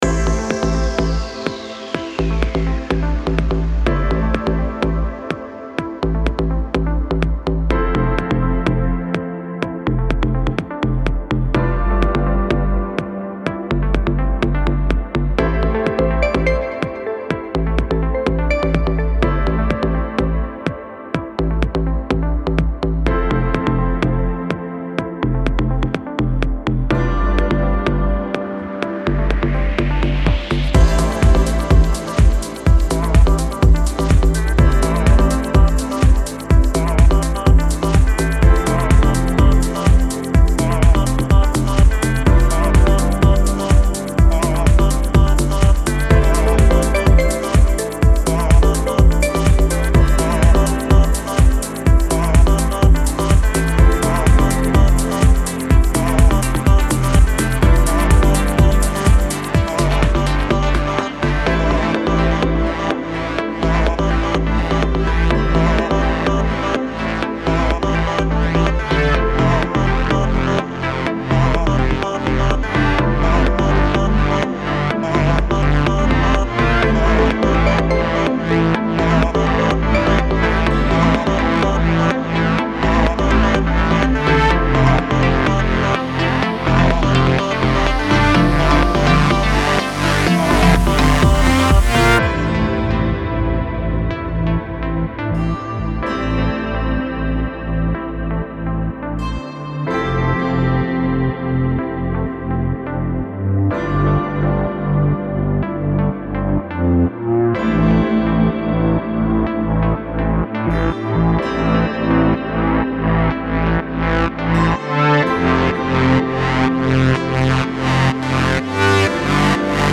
Зацените сведение A2 ( Progressive, House, Deep)
Понаковырял называется))) Кстати 12 кубейс ничего так работает - побыстрее и постабильнее 10 в некоторых моментах Не знаю , ну вроде норм, если что то заметите - ткните носом) Единственно что меня смущает мало детализации получилосЬ, это наверное из за реверов? Вокал допишу позже И подскажите...